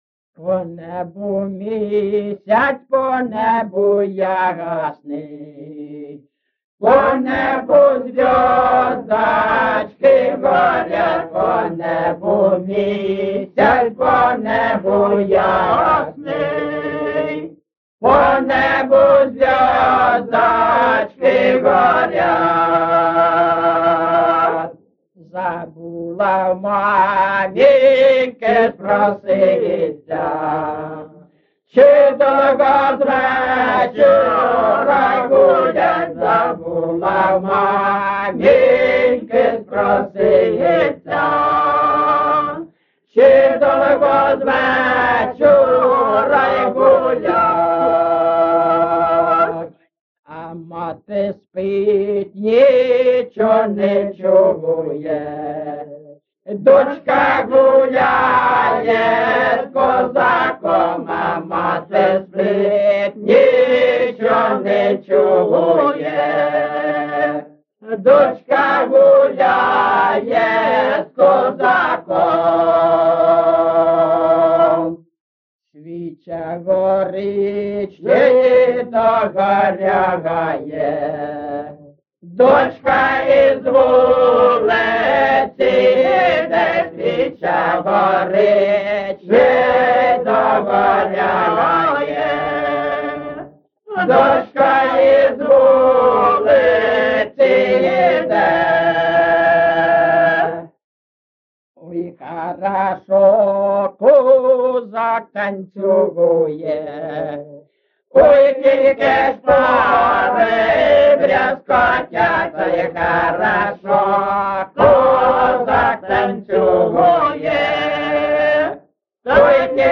GenrePersonal and Family Life
Recording locationLyman, Zmiivskyi (Chuhuivskyi) District, Kharkiv obl., Ukraine, Sloboda Ukraine